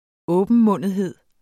Udtale [ ˈɔːbənˌmɔnˀəðˌheðˀ ]